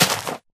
grass2.ogg